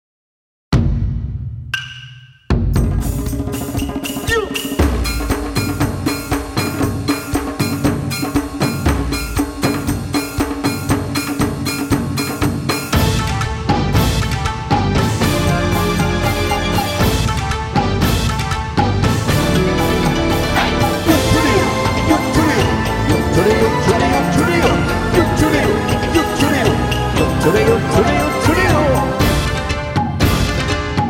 （歌あり）